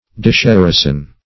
Search Result for " disherison" : The Collaborative International Dictionary of English v.0.48: disherison \dis*her"i*son\ (d[i^]s*h[e^]r"[i^]z'n), n. [See Disherit .] The act of disheriting, or debarring from inheritance; disinherison.